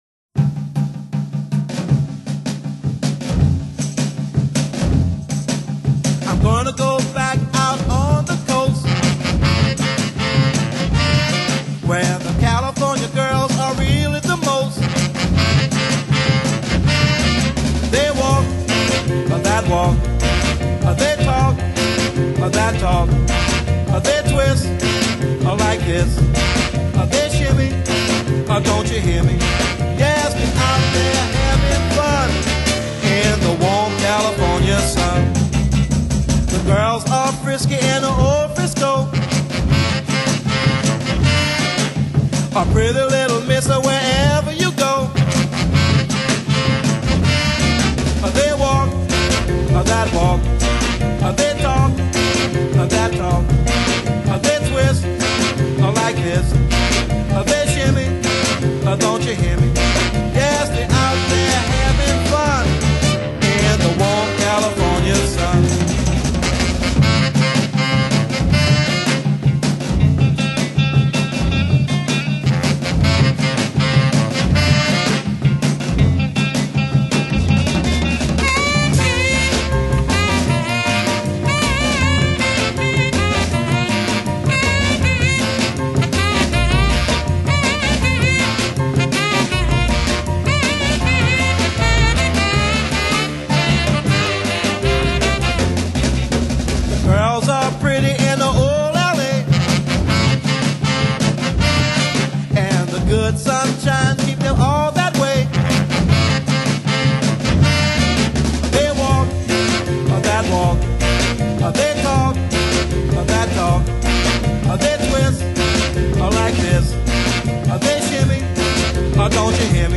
26首經典歌都是50年代和60年代的曲